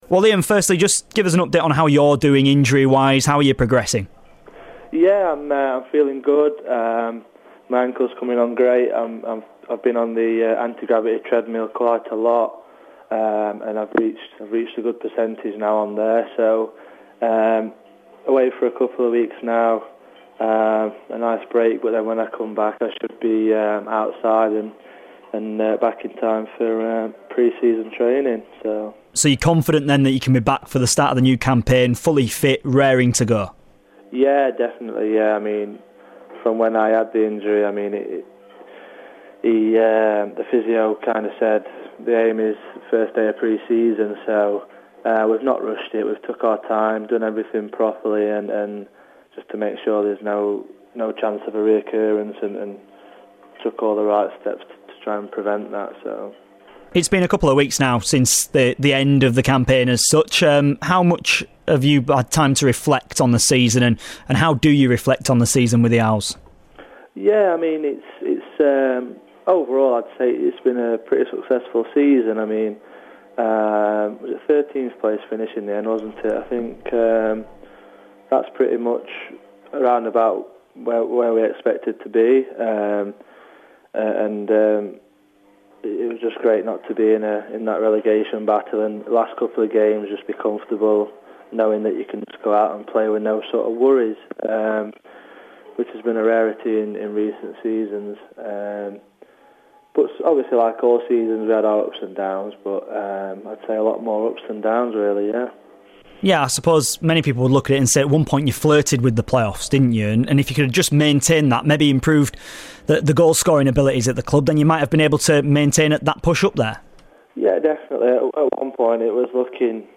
INTERVIEW: Sheffield Wednesday defender Liam Palmer on the clubs retained and released players and the future for the Owls.